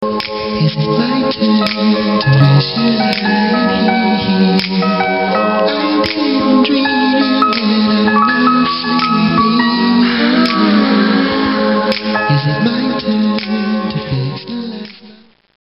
STUDIO SAMPLES (approx. 15 seconds):